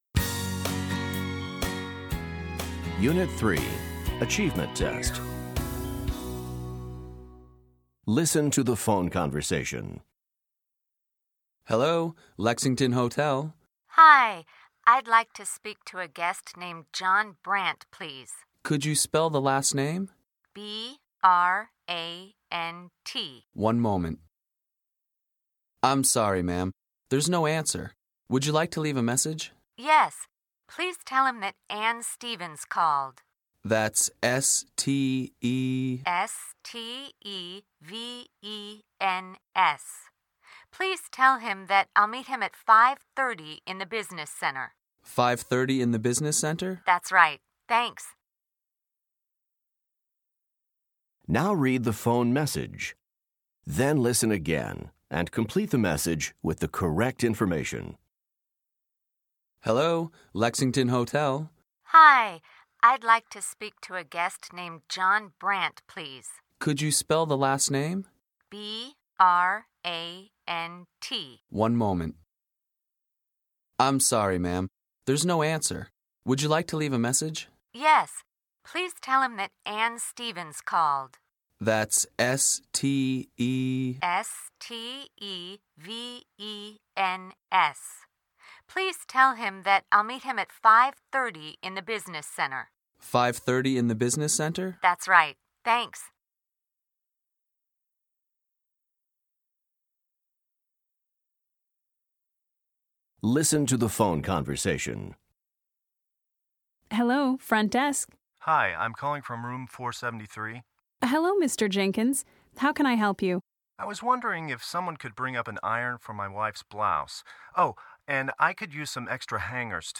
The woman is calling the Lexington …..Hotel… .